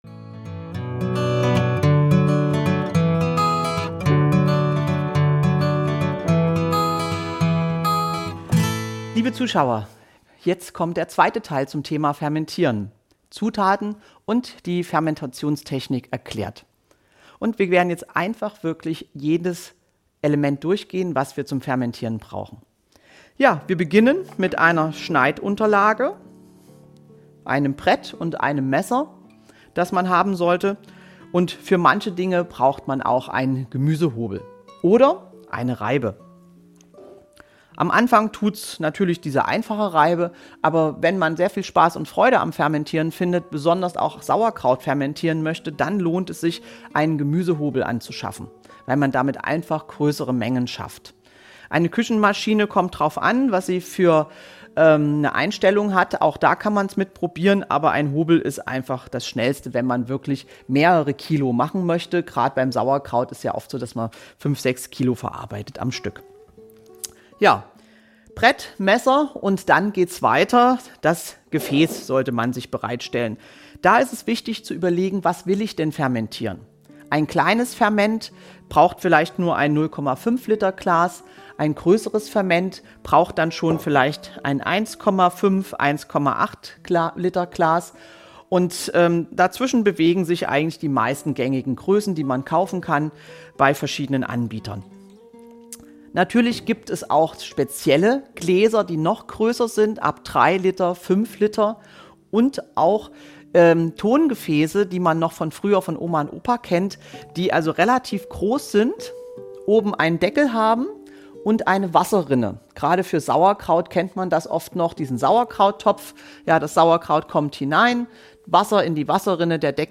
Im zweiten Teil des Vortrags werden die Grundlagen des Fermentierens vertieft. Wichtige Utensilien, die richtige Auswahl an Gläsern und die Bedeutung von Salz werden behandelt. Zudem wird auf verschiedene Fermentationstechniken eingegangen.